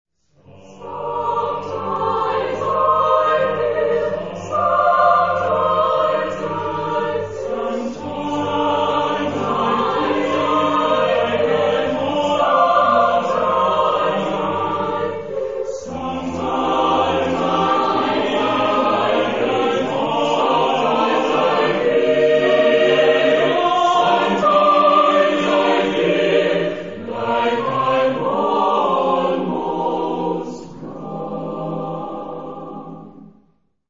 Genre-Stil-Form: geistlich ; Spiritual
Charakter des Stückes: ergreifend ; langsam ; schmerzlich
Chorgattung: SATB  (4 gemischter Chor Stimmen )
Solisten: Soprano (1)  (1 Solist(en))
Tonart(en): g-moll